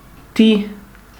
D Vokal werde in de Regel au wie im Dütsche ussgsproche: a = [a] (Roh-sursilvan-clav.ogg clav(info) ‚Schlüssel‘), i = [i] (Roh-sursilvan-ti.ogg